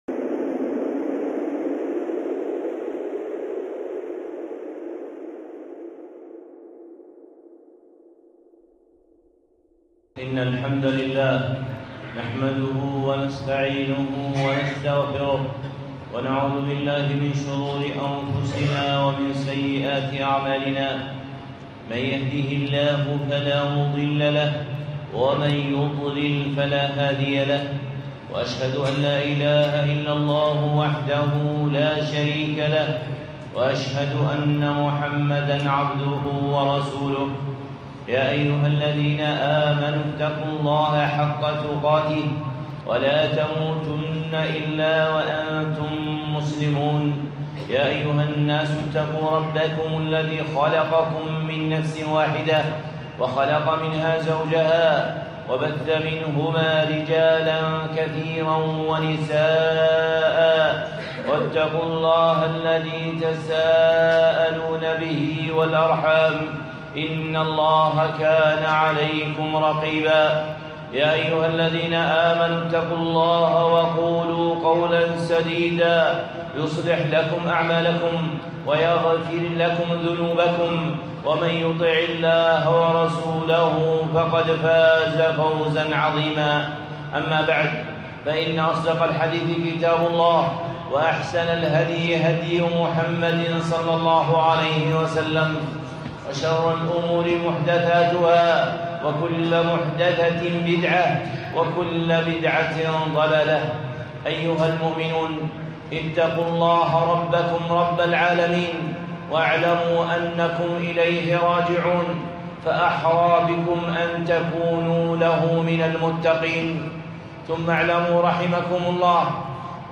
خطبة (الإيمان بالقدر) الشيخ صالح العصيمي